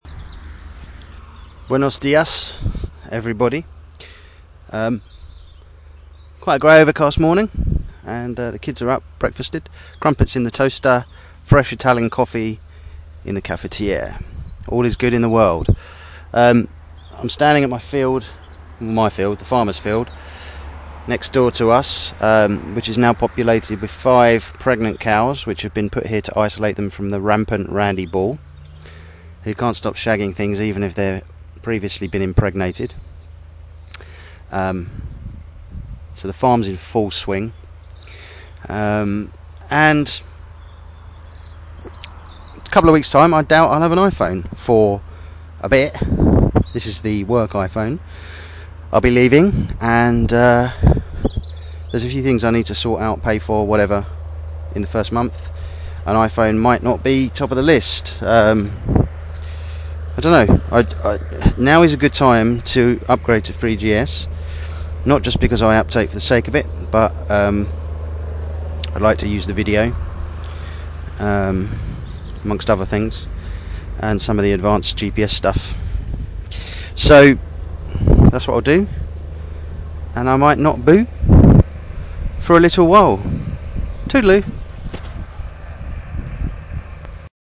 cowbells
57225-cowbells.mp3